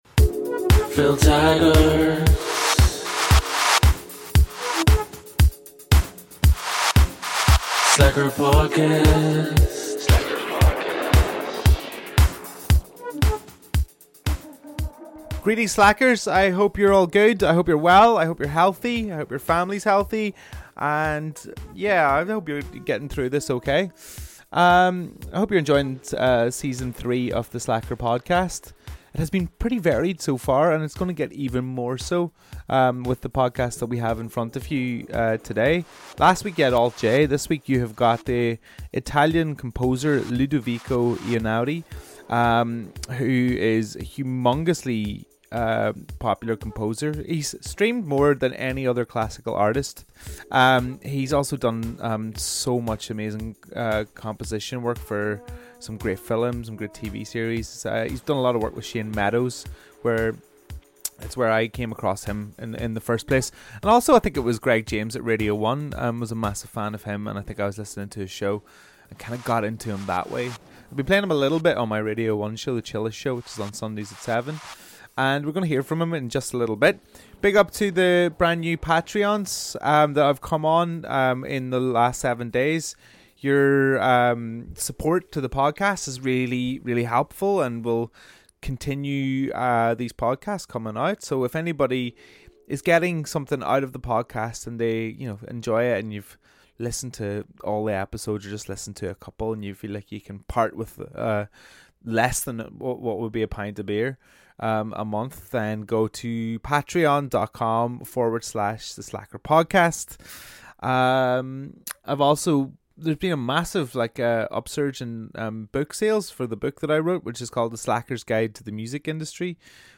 I hopped on his tour bus and travelled with him to Heathrow Airport. Our first recording in transit.